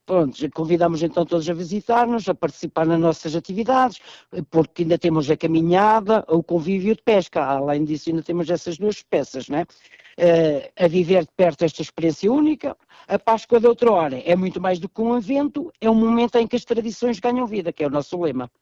Com elevadas expectativas, Ulisses Santos apela à participação de macedenses e visitantes nesta iniciativa, que todos os anos atrai muitos curiosos: